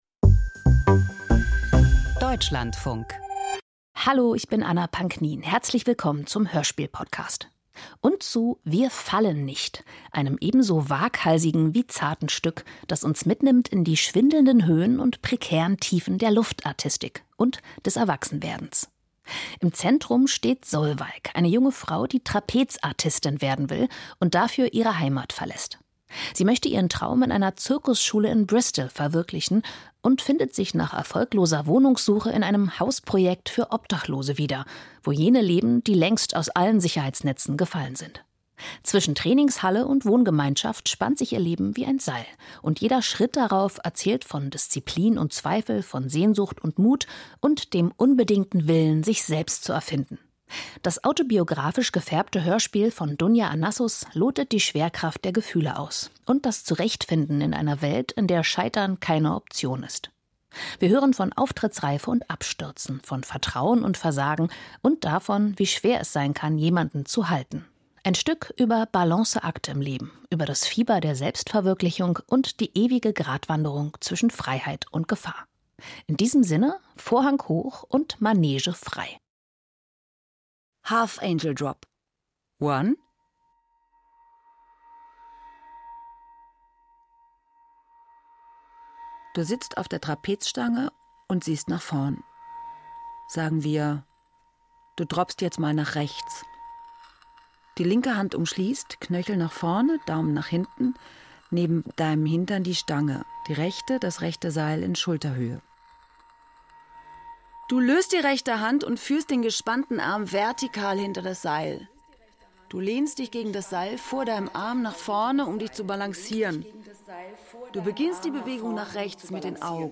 Wir fallen nicht - Hörspiel: Ein Leben als Trapezkünstlerin ~ Hörspiel Podcast